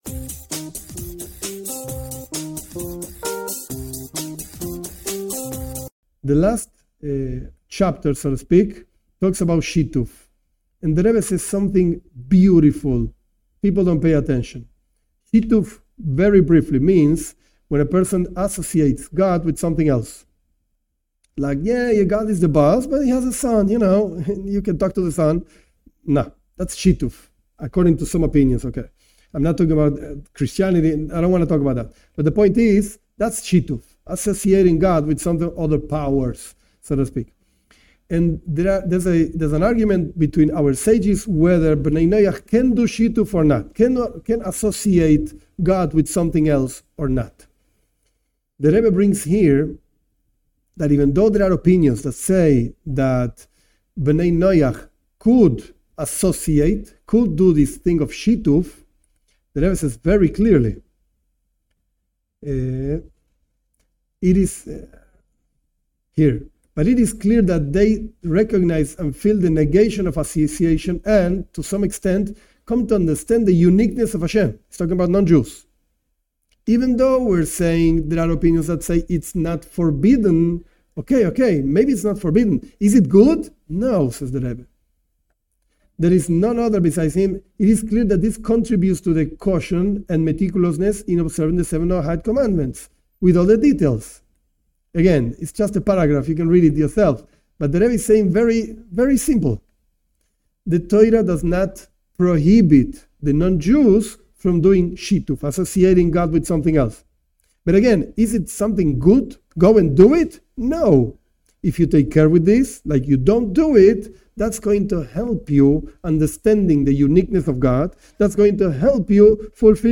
This class is a commentary on the concept of idolatry and association for Bnei Noach. The complete class is a review of the text of a Talk by The Rebbe on the 19th of Kislev (1983-1984) where the Rebbe explains a letter written by the founder of Chabad, Rabbi Shneur Zalman of Liadi, regarding his liberation from the imprisonment in Zarist Russia in 1789.